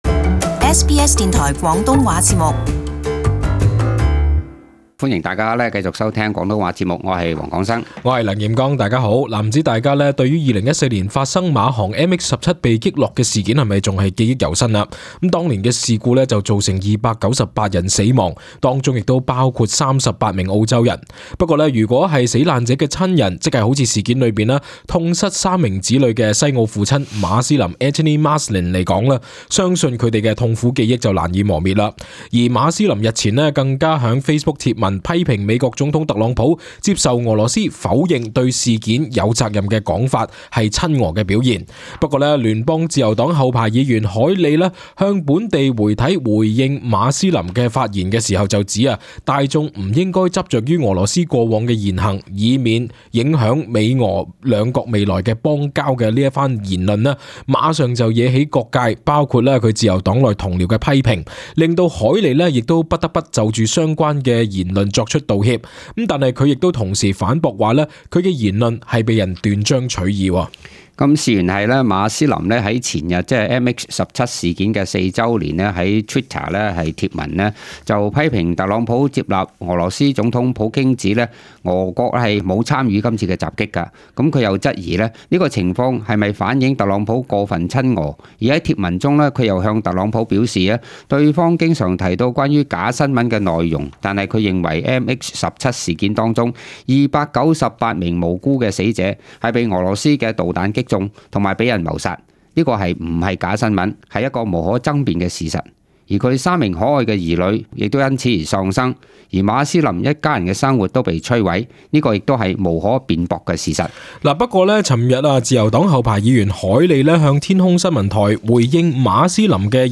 【時事報導】凱利回應澳父MH17貼文言論惹批評